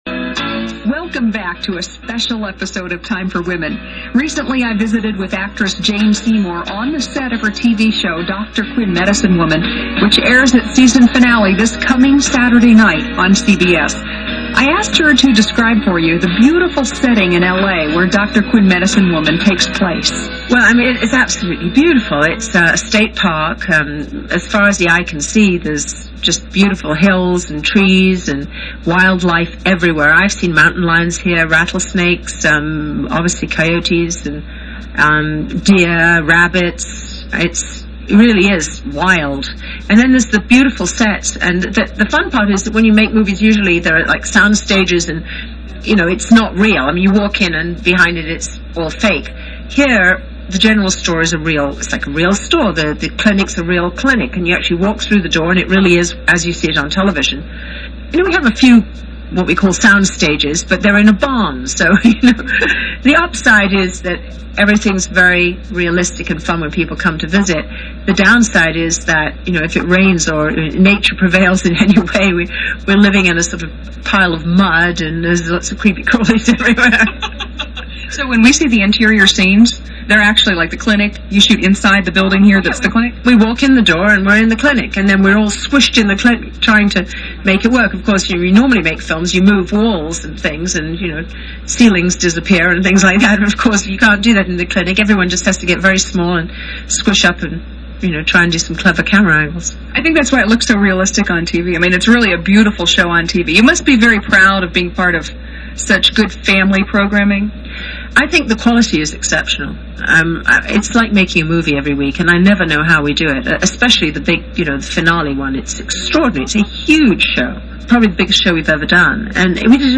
Jane Seymour on the set of Dr. Quinn